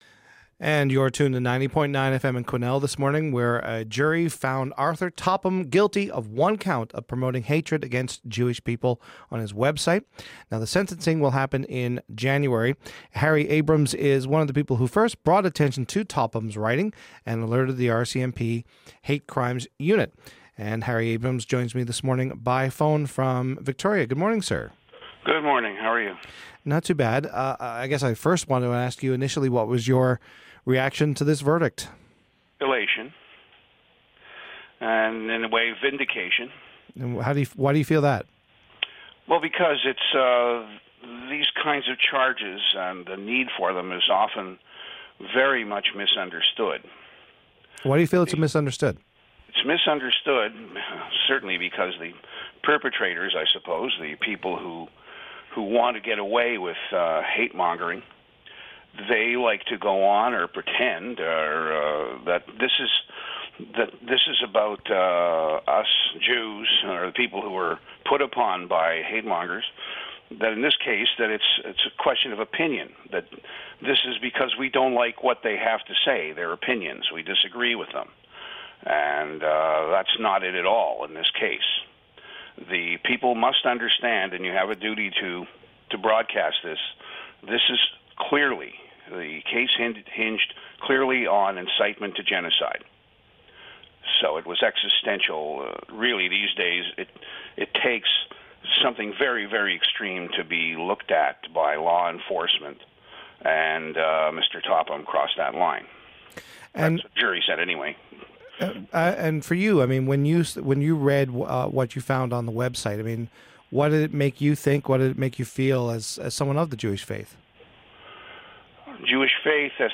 We speak to him from his home in Victoria.